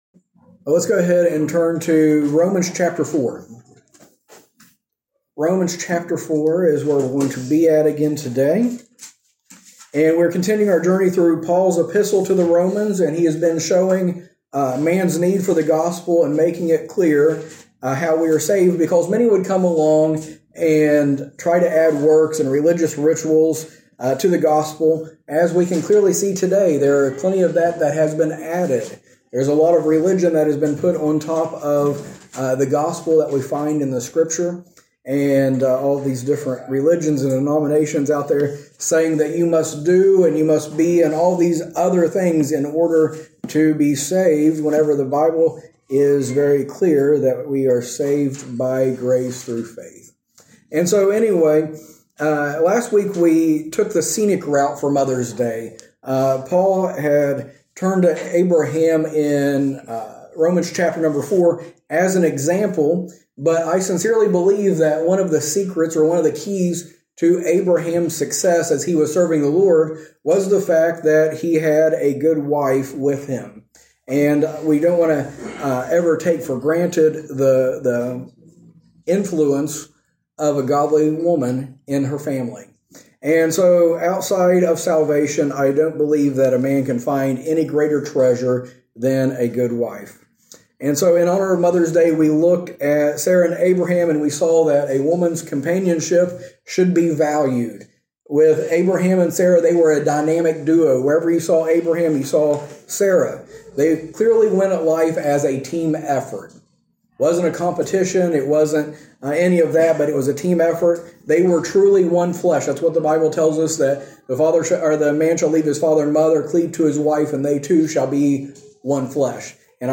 In this sermon series, we go through Paul's letter to the Romans section by section as he shows how the Christian life should be.